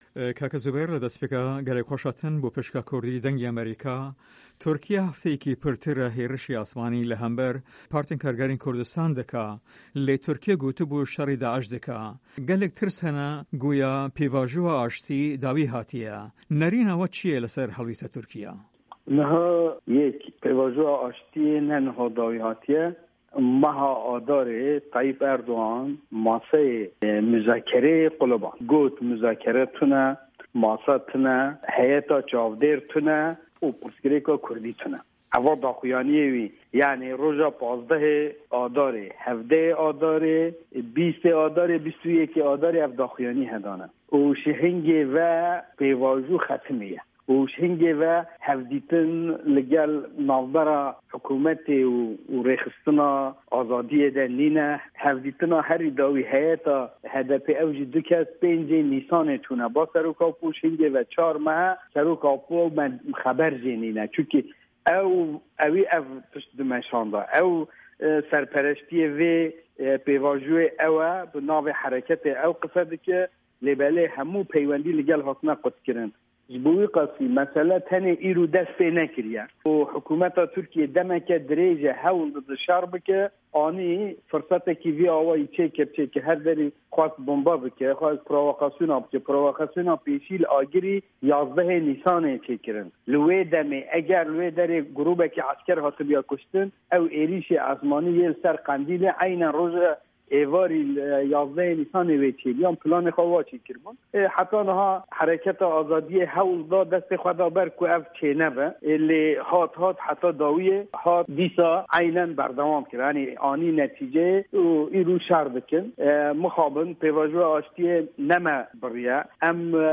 زوبێر ئاێدار‌ ئه‌ندامی کونسه‌یا کۆما جڤاکن کوردستان KCK له‌ هه‌ڤپه‌یڤینێکدا له‌گه‌ڵ به‌شی کوردی ده‌نگی ئه‌مه‌ریکا ده‌ڵێت" دانۆستاندنه‌کان کۆتایی پێی نه‌هاتوه‌، به‌ڵام ره‌جه‌ب ته‌یب ئه‌ردوگان له‌ مانگی ئازار وتو وێژه‌کانی هه‌ڵگه‌راند و ووتی وتو وێژکردن نییه‌، ماف نییه‌، کۆمیته‌ی چاودێر نییه‌ و پرسێکی کوردیش نییه‌ و به‌م جۆره‌ پرۆسه‌که‌ کۆتایی پێیهات.